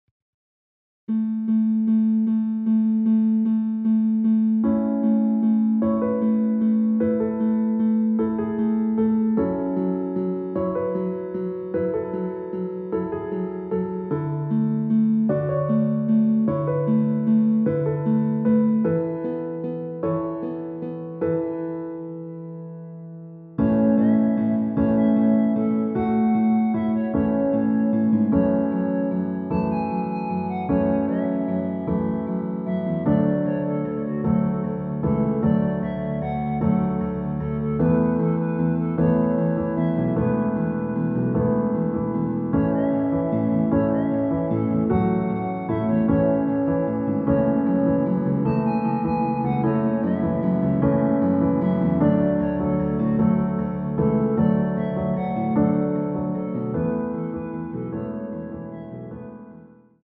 원키에서(-3)내린 멜로디 포함된 MR입니다.(미리듣기 확인)
앞부분30초, 뒷부분30초씩 편집해서 올려 드리고 있습니다.